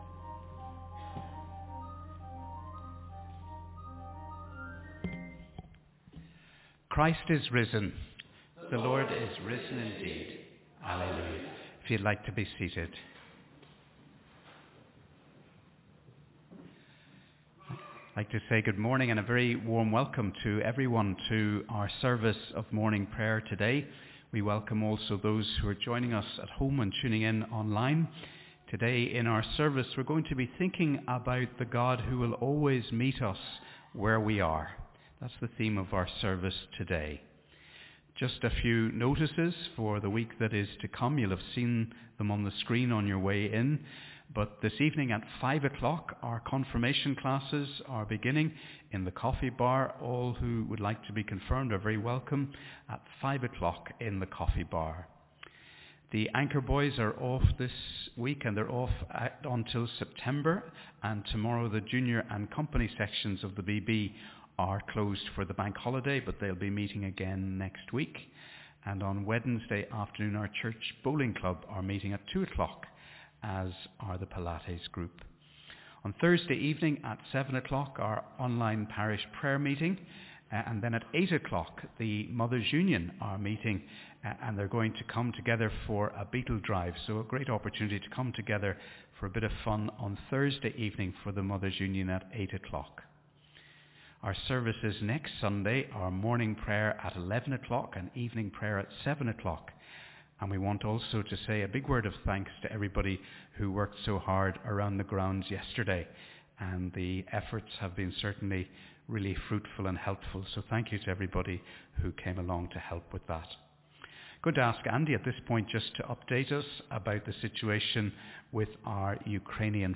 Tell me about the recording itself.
Wherever you are, we warmly welcome you to our service of Morning Prayer on this 3rd Sunday of Easter.